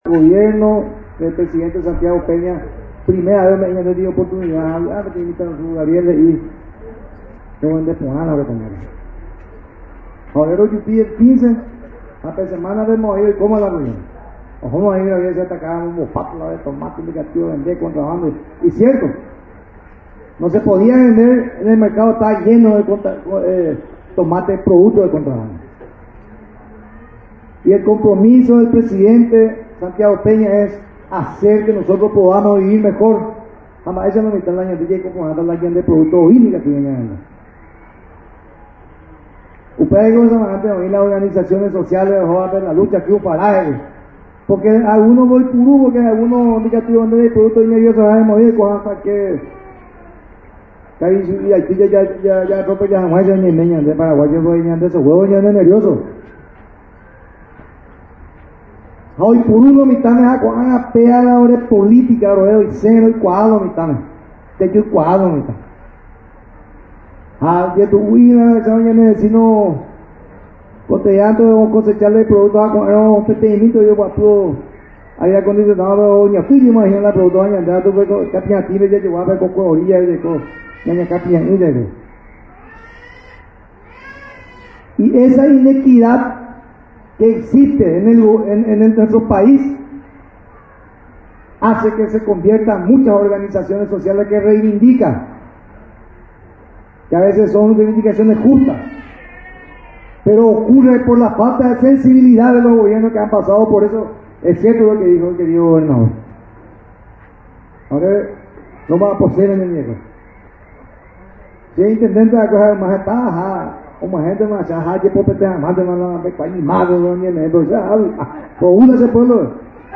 AUDIO: DR. CARLOS GIMÉNEZ-MINISTRO DE AGRICULTURA Y GANADERÍA